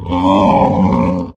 boar_panic_0.ogg